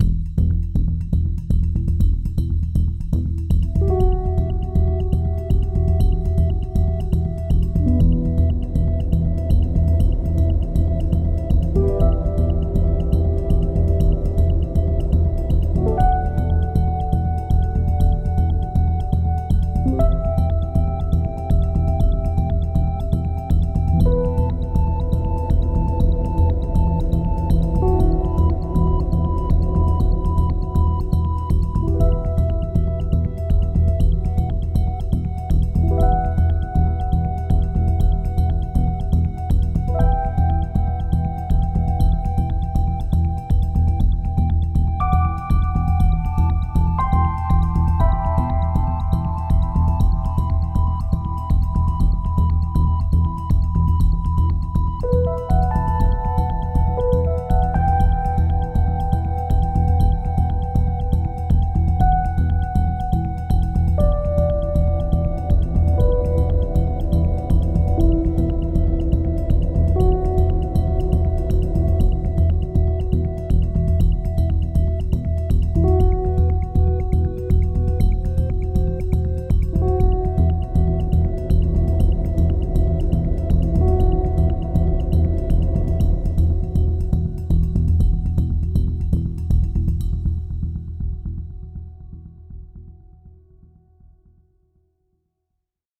Added Ambient music pack. 2024-04-14 17:36:33 -04:00 26 MiB Raw Permalink History
Ambient Concern Intensity 2.wav